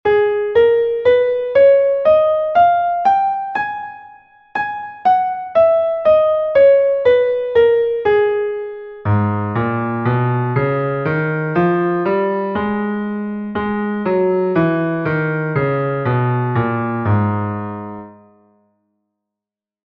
Gis-Moll, Tonleiter aufwärts und abwärts
Gis-Moll.mp3